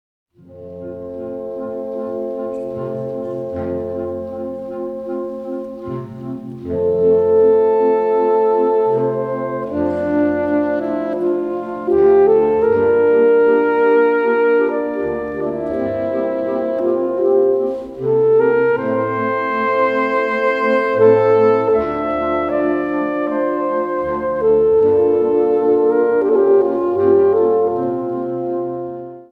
Instrumentation Ha (orchestre d'harmonie)